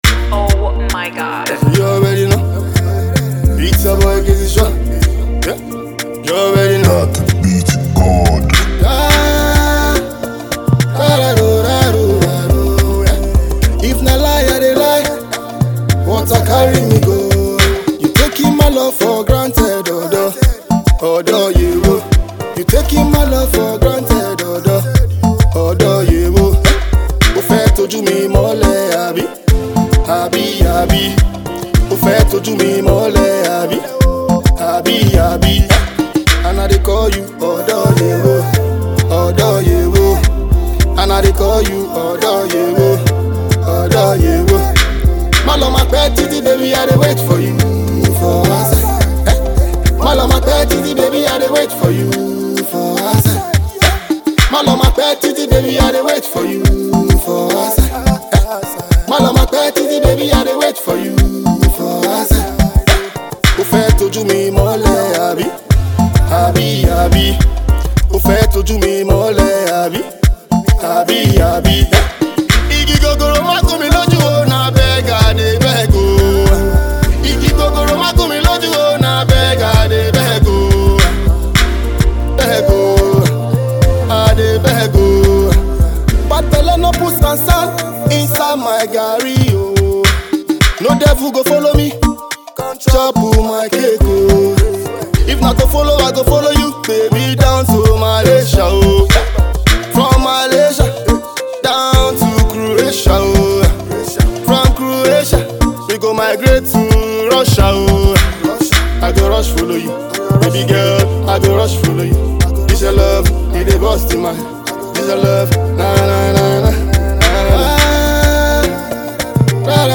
Afrobeat Art
Enjoy this Melodious Tune & Get The Video Below. https